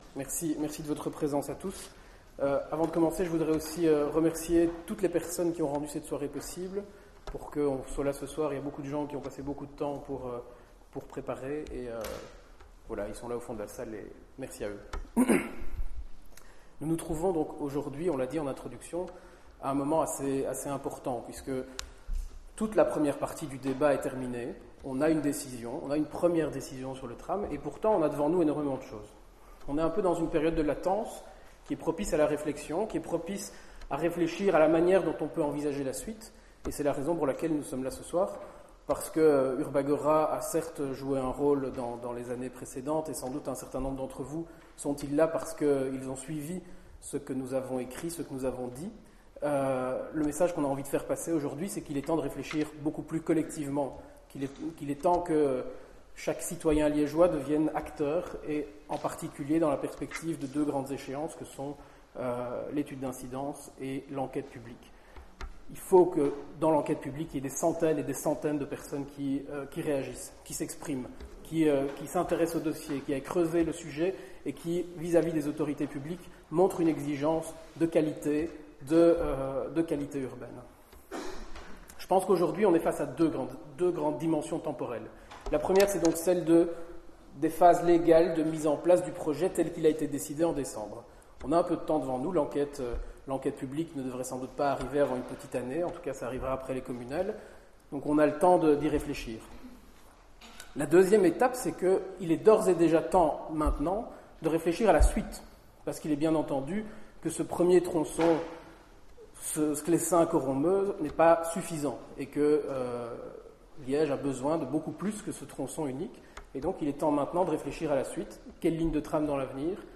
Ce soir, urbAgora organisait une soirée pour faire le point sur le dossier du tram, dont l’étude d’incidence devrait débuter dans les prochaines semaines ou mois.
Voici l’enregistrement du discours que j’ai prononcé à cette occasion :